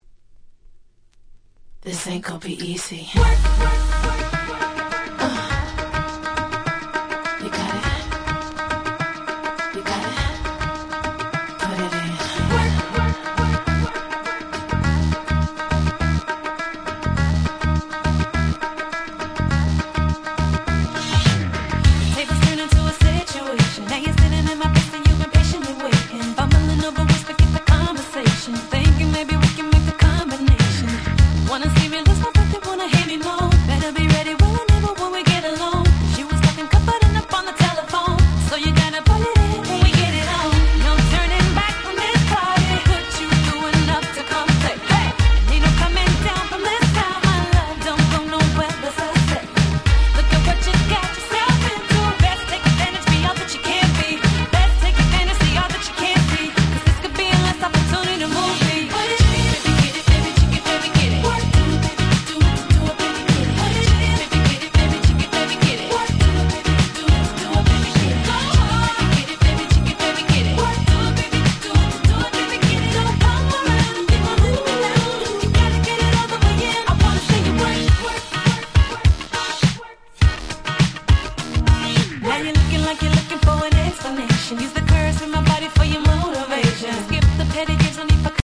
White Press Only Remixes !!
Hip Hop R&B 00's